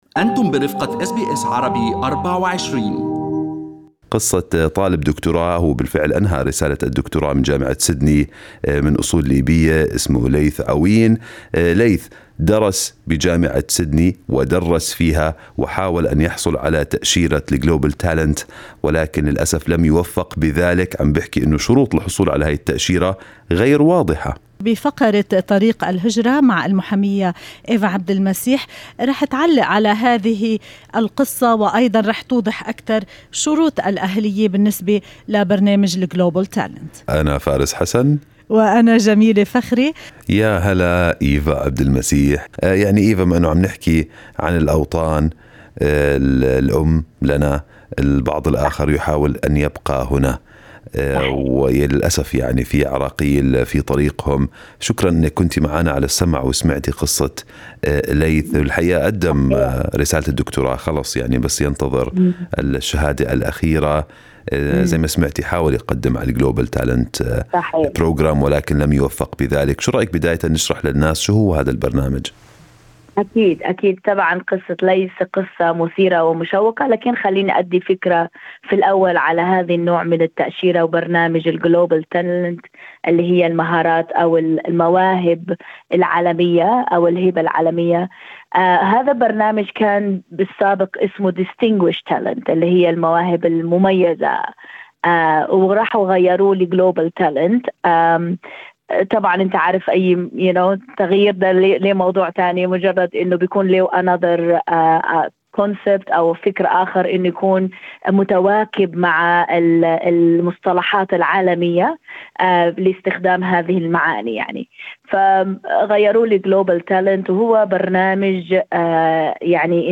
في لقاء مع اس بي اس عربي24